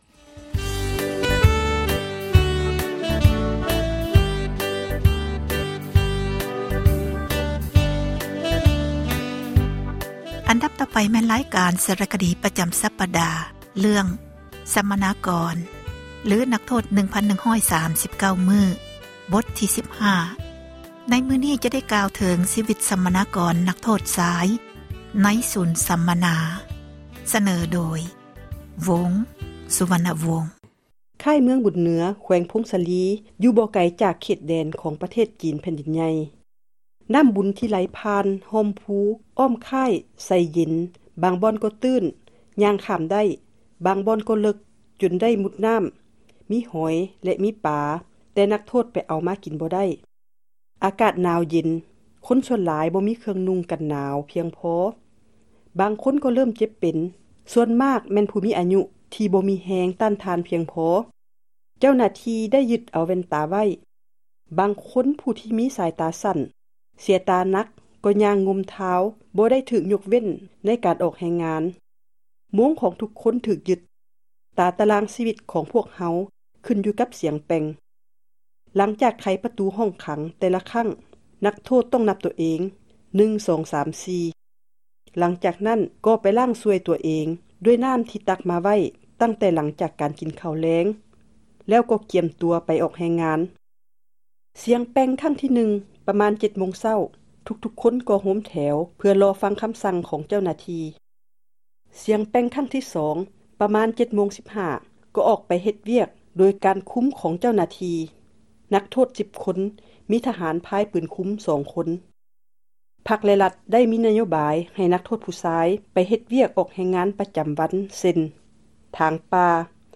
ສາຣະຄະດີ ເຣື້ອງ ສັມມະນາກອນ ຫຼື ນັກໂທດ 1,139 ມື້, ບົດທີ 15, ຈະ ໄດ້ກ່າວເຖິງ ຊິວິດ ຂອງ ສັມມະນາກອນ ນັກໂທດຊາຍ ໃນສູນ ສັມມະນາ.